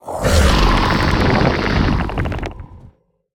sounds / mob / warden / roar_3.ogg
roar_3.ogg